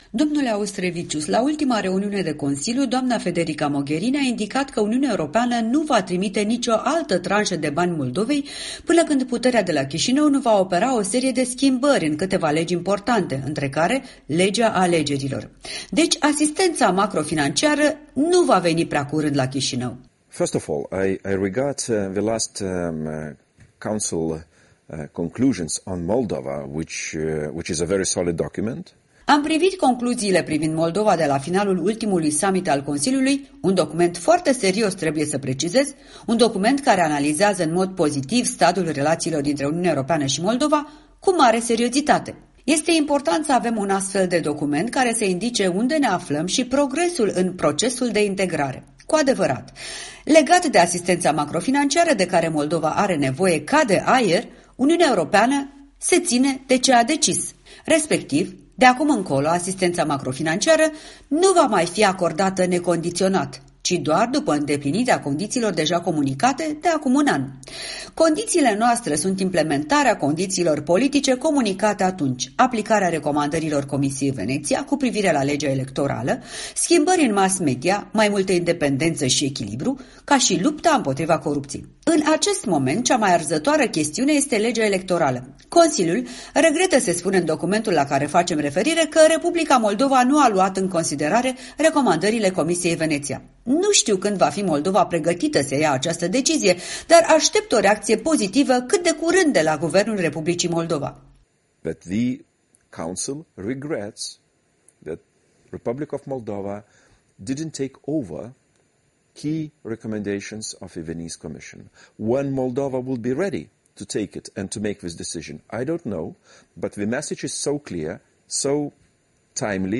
Un interviu la Strasbourg cu europarlamentarul lituanian (ALDE).